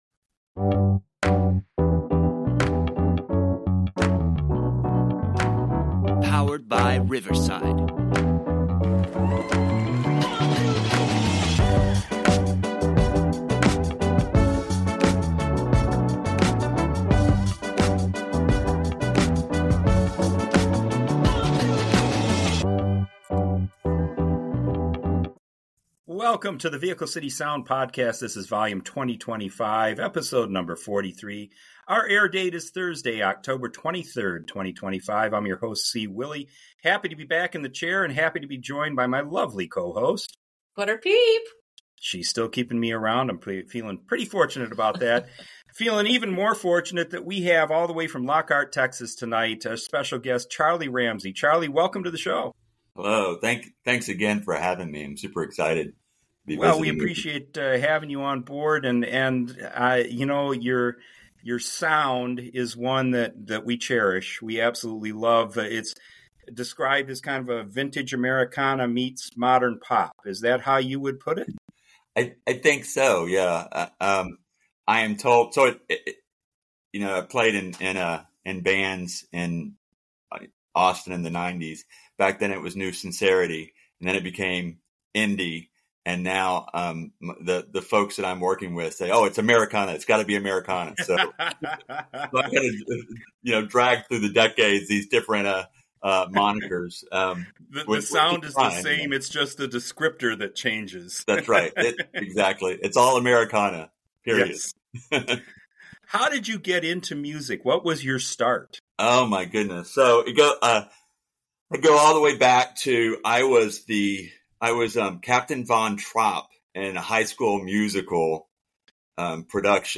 His Americana/Pop sound will bring you in, and his authentic story telling will make you a fan.&nbsp